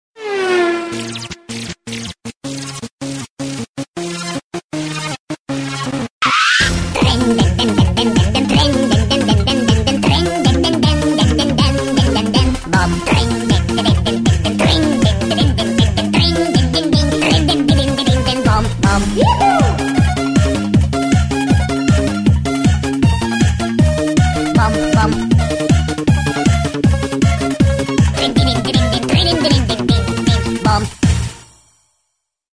• Качество: 192, Stereo
смешные